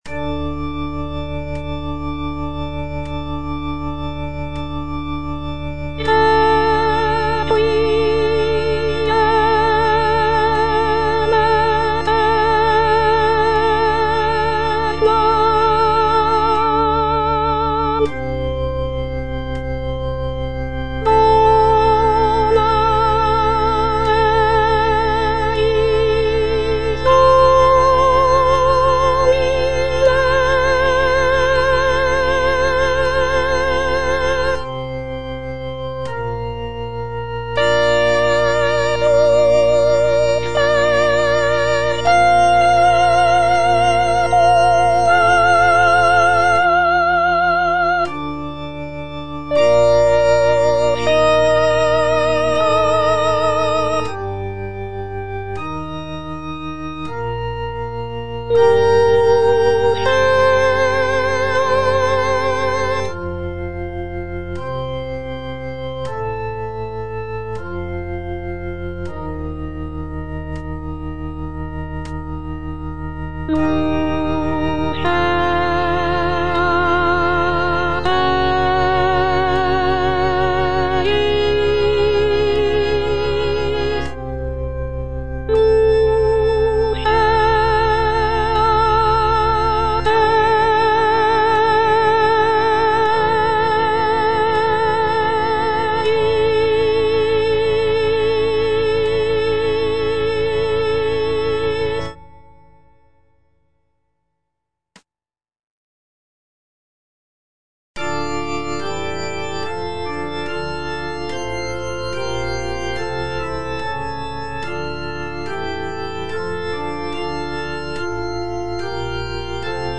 G. FAURÉ - REQUIEM OP.48 (VERSION WITH A SMALLER ORCHESTRA) Introït et Kyrie - Soprano (Voice with metronome) Ads stop:
Gabriel Fauré's Requiem op. 48 is a choral-orchestral work that is known for its serene and intimate nature.
This version features a reduced orchestra with only a few instrumental sections, giving the work a more chamber-like quality.